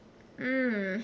Prosodic Patterns in English Conversation
mmm-not-really.wav